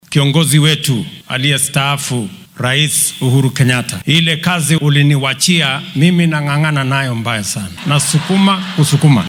Hoggaamiyayaashan ayaa maanta ka qayb galay caleema saarka hoggaamiye kaniiseed oo ka dhacday ismaamulka Embu.